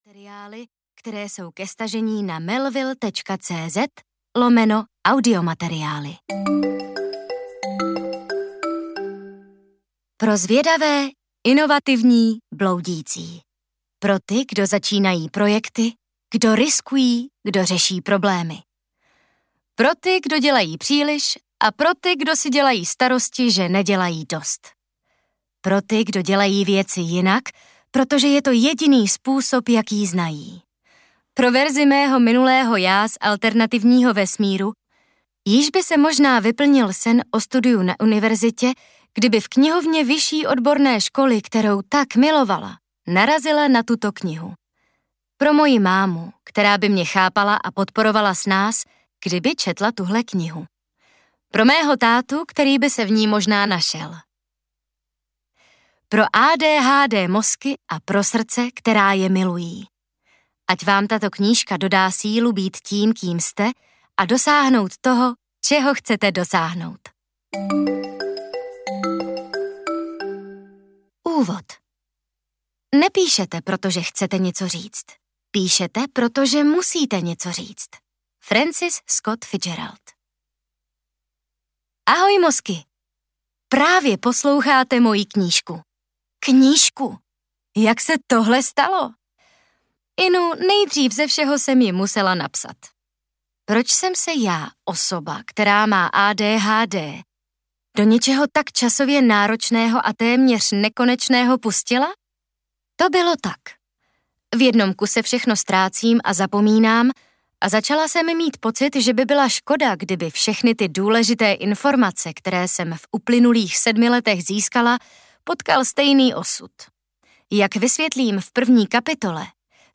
Jak na ADHD audiokniha
Ukázka z knihy